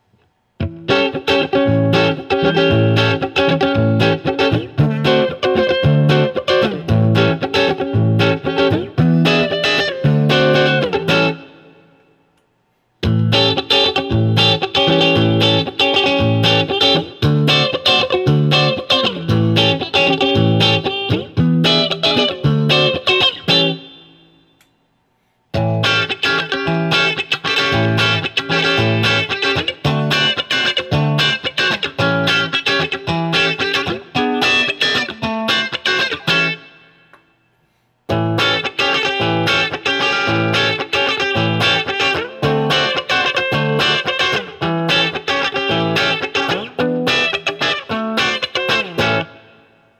I put the guitar through my usual rig which is the Axe-FX Ultra into the QSC K12 speaker using the Tiny Tweed, JCM 800, and Backline settings.
Tweed 7th Chords
Each recording goes though all of the pickup selections in the order: neck, both (in phase), both (out of phase), bridge. All guitar knobs are on 10 for all recordings.